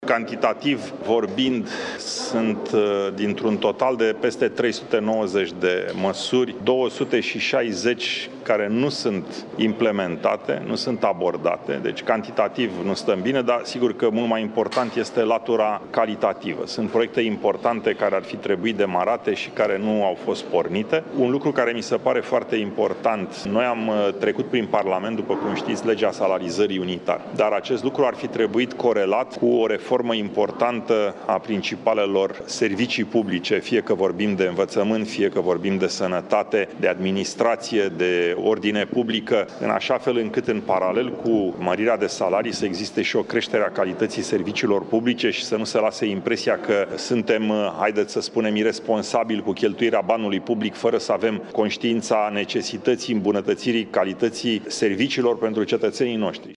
Miniştrii ALDE şi-au scris demisiile, a anunţat liderul Alianţei, Călin Popescu Tăriceanu: